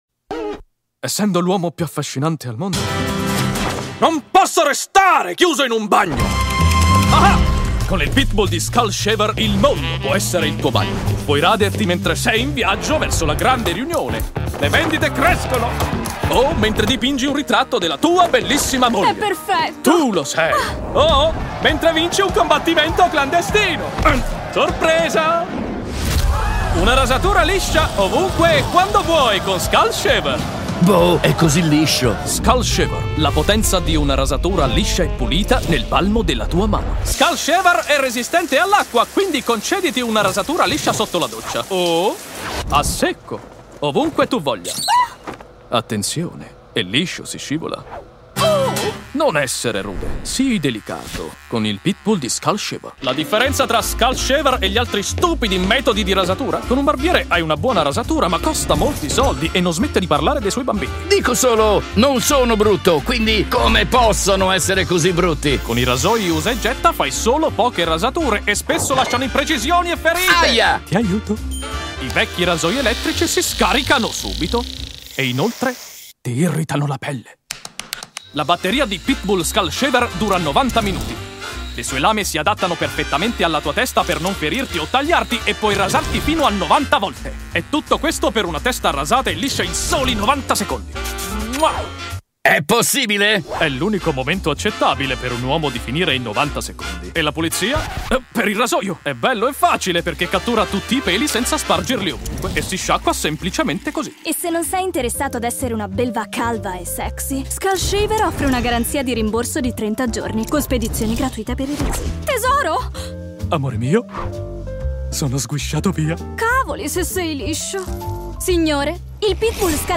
Professional Voice Actor